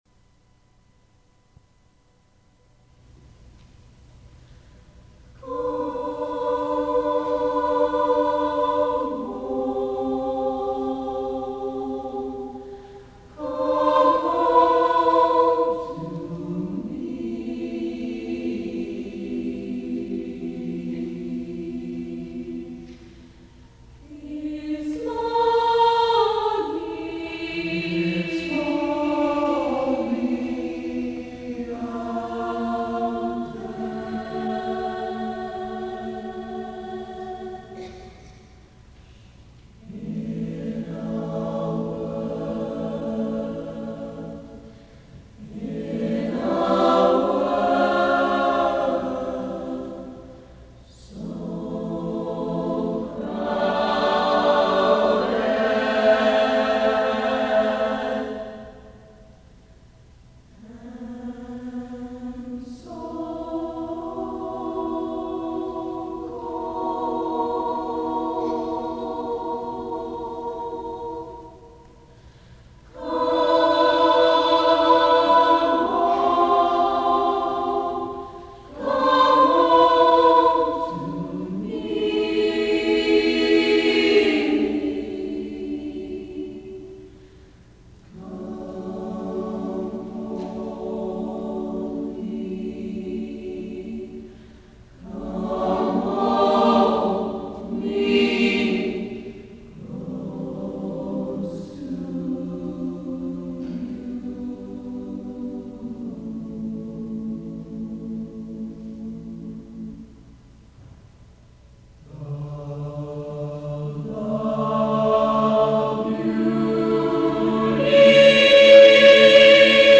1994-1995 Choir Recordings
The school year of 1994-1995 was very fun, choir-wise. we performed some great jazz songs (see Blue Skies and Come Home), a jazzed-up vocal version of Bach’s Organ Fugue, and some high-energy crowd pleasers (Bridge Over Troubled Water, Steal Away, and Betelehemu).